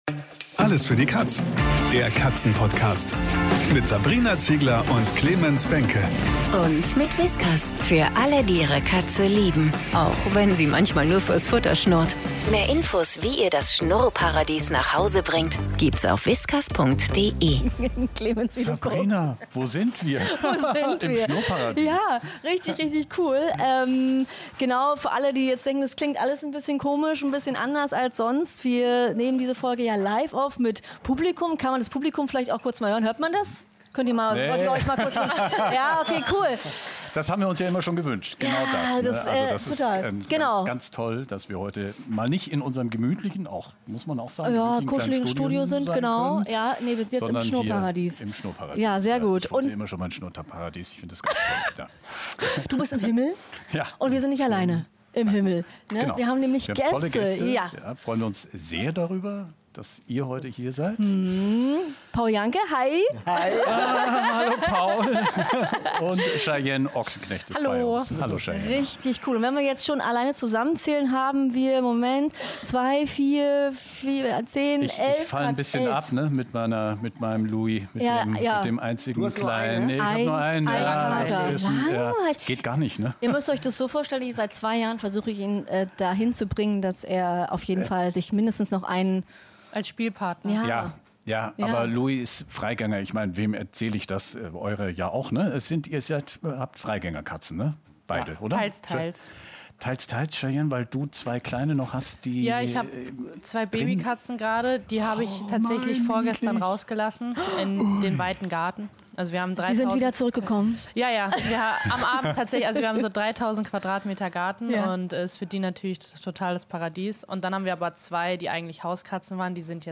Zum allerersten Mal wurde diese Folge live mit Publikum aufgenommen! Und als wäre das nicht aufregend genug, sind auch noch echte Promis am Start – DER Bachelor Paul Janke und Cheyenne Ochsenknecht quatschen mit uns über ihr Leben mit ihren Katzen. Insgesamt 11 Samtpfoten sorgen in dieser Folge für Action: von lustigen Macken über kleine Chaosmomente bis hin zu der Frage, wie es eigentlich ist, mit so vielen Katzen zu daten.